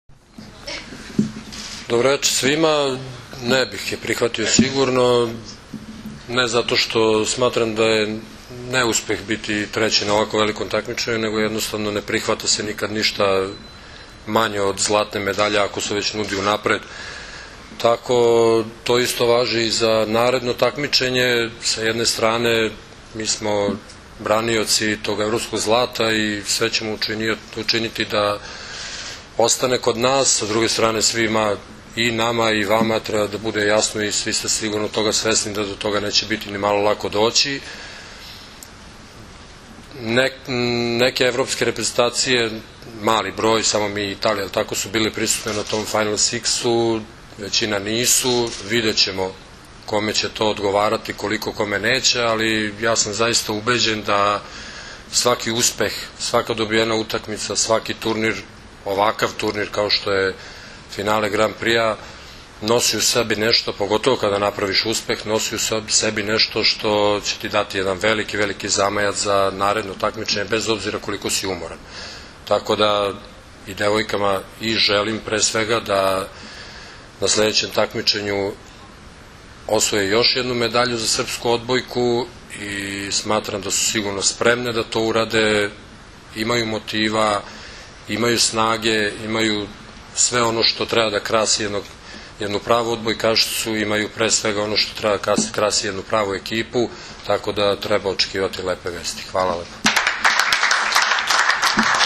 U salonu „Beograd“ aerodroma „Nikola Tesla“ održana je konferencija za novinare, na kojoj su se predstavnicima medija obratili Maja Ognjenović, Brankica Mihajlović, Milena Rašić, Jovana Brakočević i Zoran Terzić.
IZJAVA ZORANA TERZIĆA